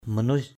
/mə-nuɪ’s/